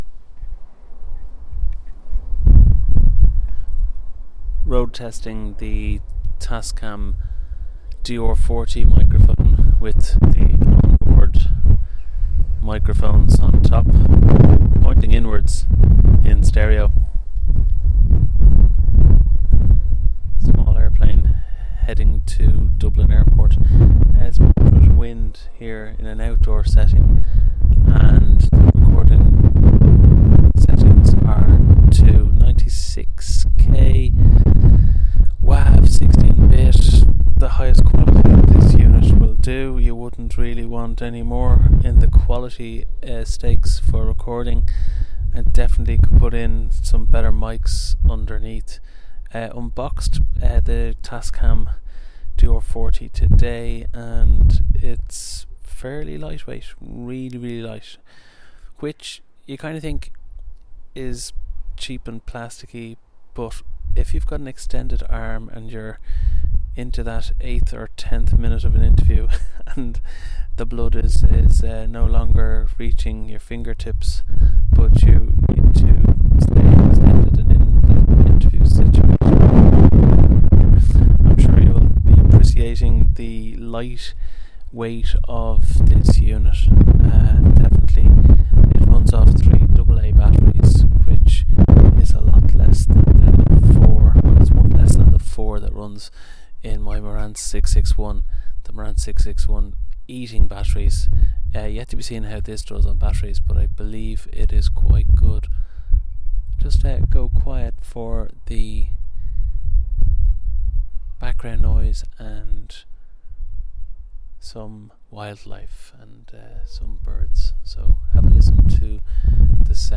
wav 16bit 96k on board mics pointing inward